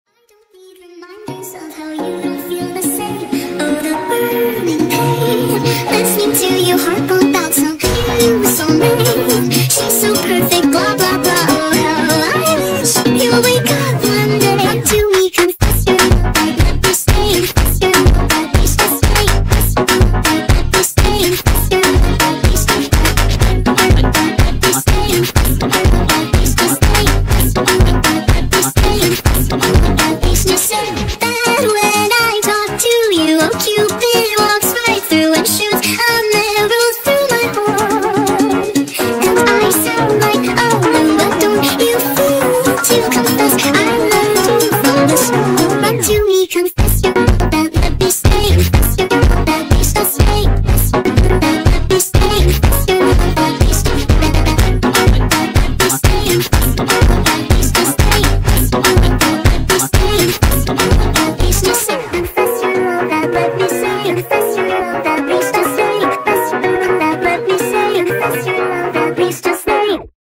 با ریتمی تند و پر انرژی
فانک